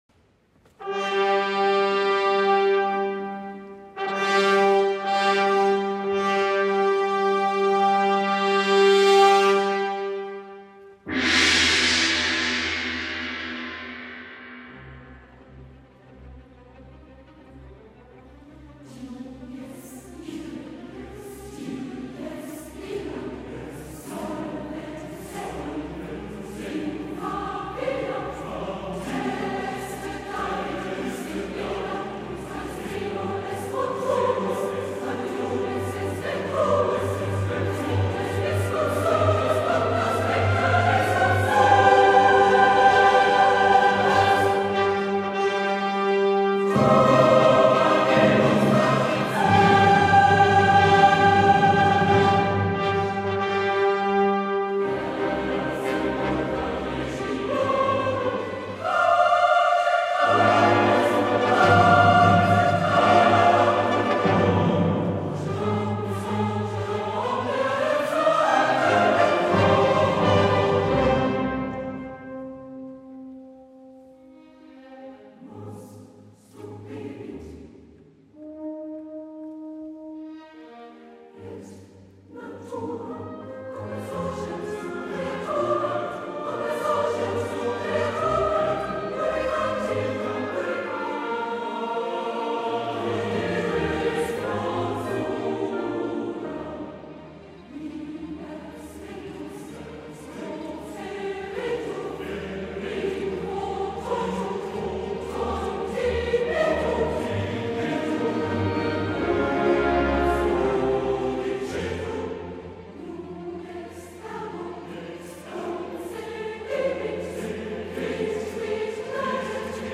Kantorei St. Peter und Paul Losheim Kinderchor „Happy Kids“
Hier finden Sie ausgewählte Hörproben unseres Chores bei verschiedenen Anlässen und Gastauftritten.
Konzert-Ausschnitte